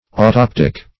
Search Result for " autoptic" : The Collaborative International Dictionary of English v.0.48: Autoptic \Au*top"tic\ ([add]*t[o^]p"t[i^]k), Autoptical \Au*top"tic*al\ (-t[i^]k*al), a. [Gr. a'ytoptiko`s: cf. F. autoptique.]
autoptic.mp3